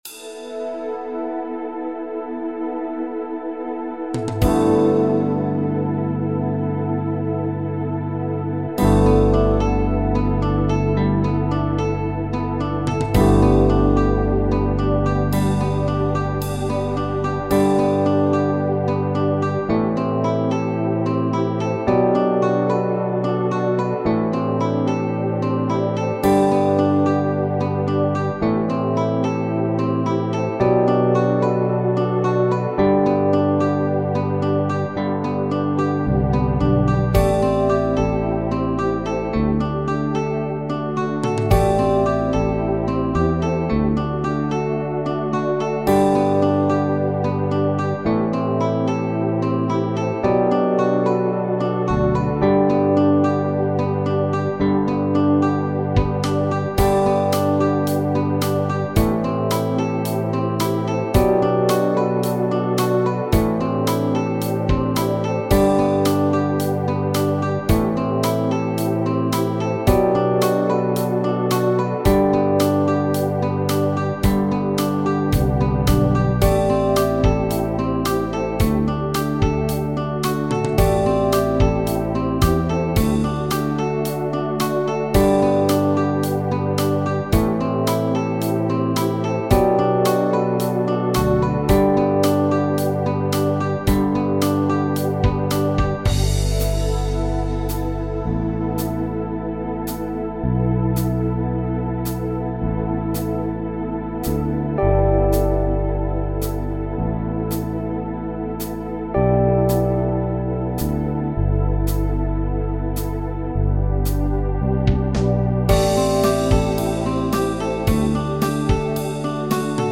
Ich_hab_ein_zaertliches_Gefuehl__5_Playback.mp3